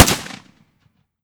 fps_project_1/30-30 Lever Action Rifle - Gunshot B 005.wav at ba18dd30f58b5251f2ddf2e288245ee65ff4604a